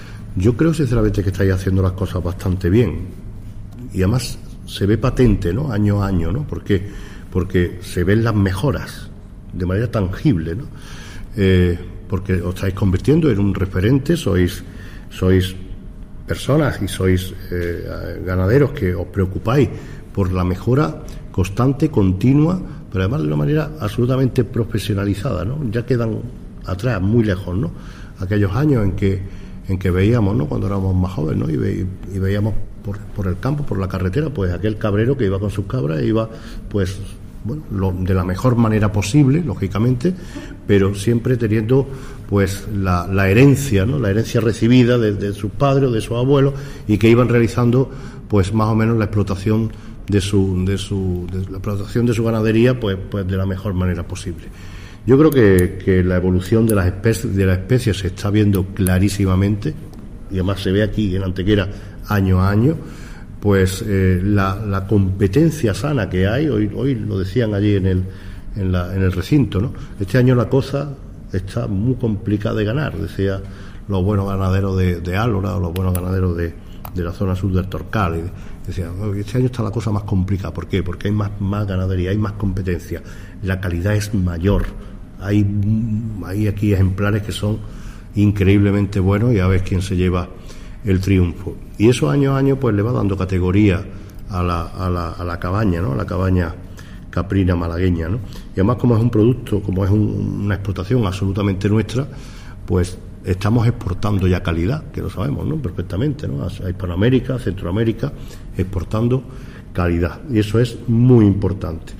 El Alcalde de Antequera inaugura las Jornadas Técnicas Caprinas que se desarrollan en la tarde de hoy en el marco de AGROGANT
Cortes de voz M. Barón 766.07 kb Formato: mp3